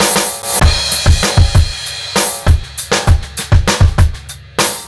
O BEAT 1  -L.wav